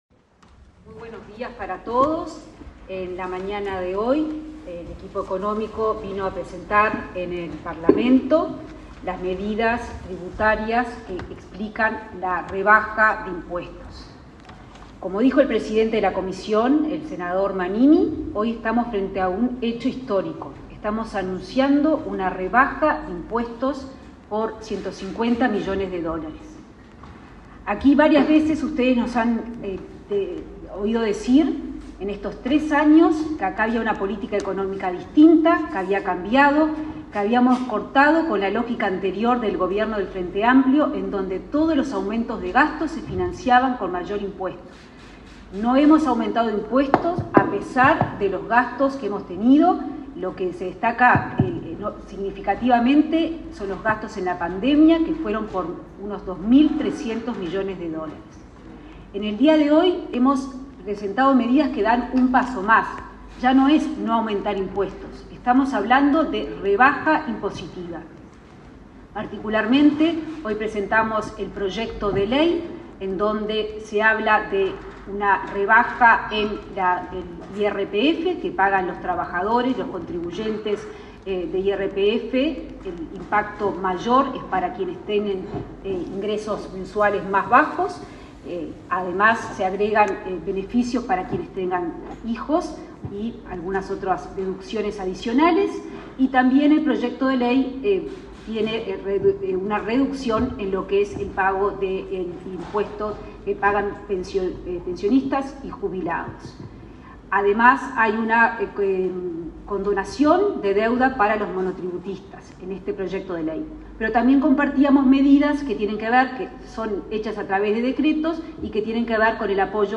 Conferencia de la ministra de Economía, Azucena Arbeleche
Luego, la ministra Azucena Arbeleche realizó una conferencia de prensa para informar sobre el alcance de la reunión.